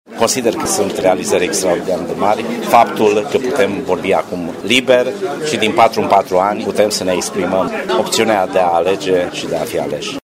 Ședință festivă a CL Timișoara, la 30 de ani de la Revoluția din decembrie 1989 - Radio România Timișoara